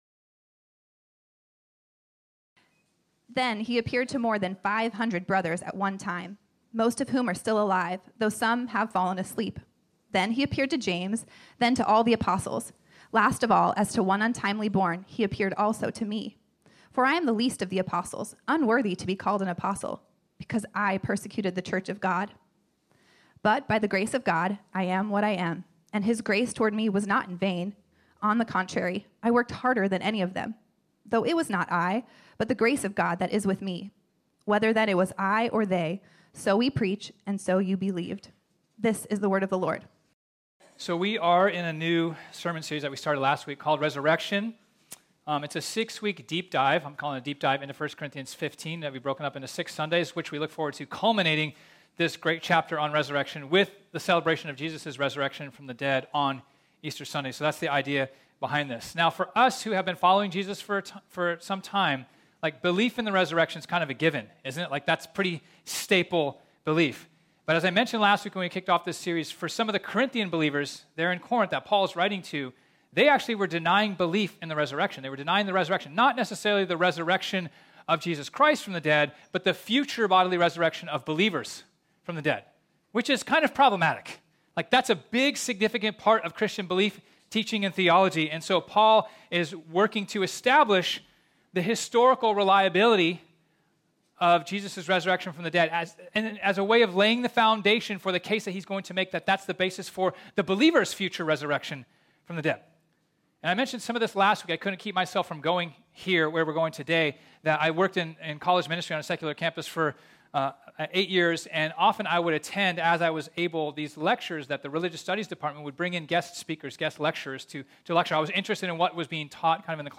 This sermon was originally preached on Sunday, March 20, 2022.